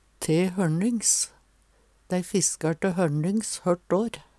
te høLnings - Numedalsmål (en-US)